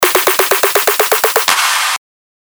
LP 248 – SNARE ROLL – EDM – 124BPM